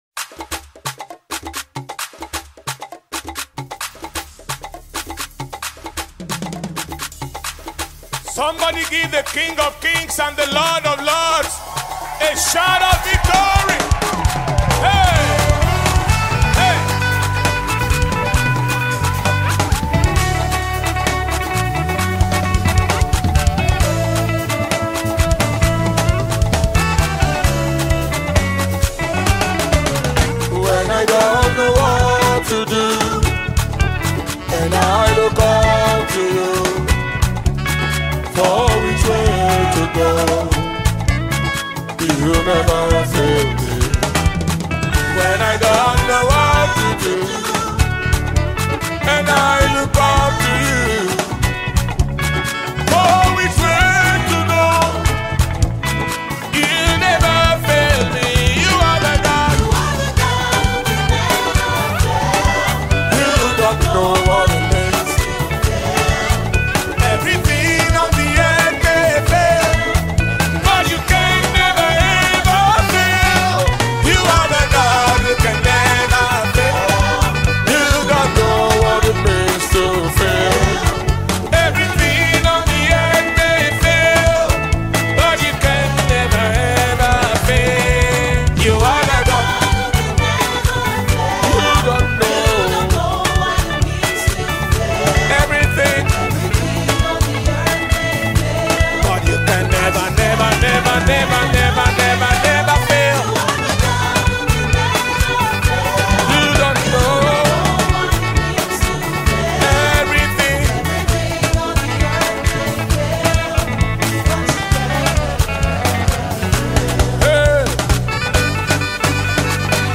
brand new groovy song of praise